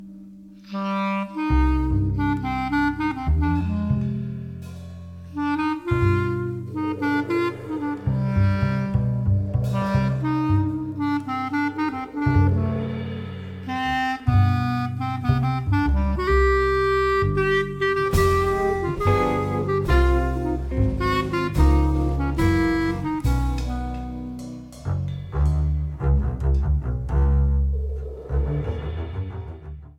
clarinet, bass clarinet, tenor saxophone
electric guitar
double bass
drums
bandoneón on tracks 1
Recorded on April 15, 2018, at Tracking Room, Amsterdam.